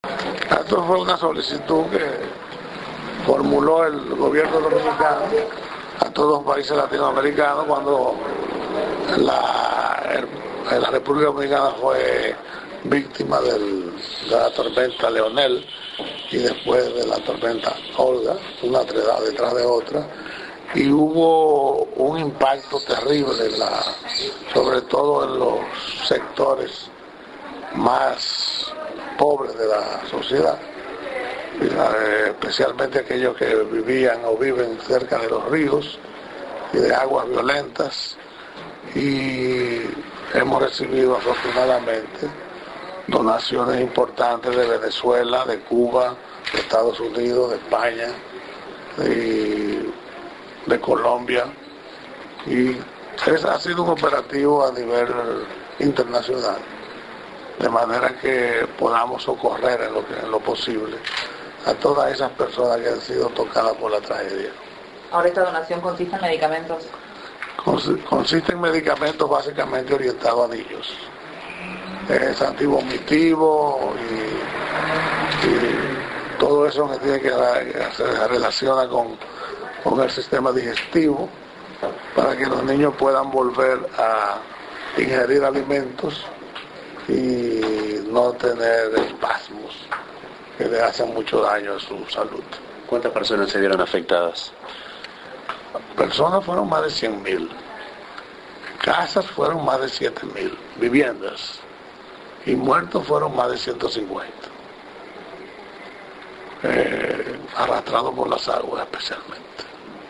Declaraciones del Embajador de República Dominicana en Uruguay, Silvio Peña, al recibir una donación humanitaria por parte del Sistema Nacional de Emergencias.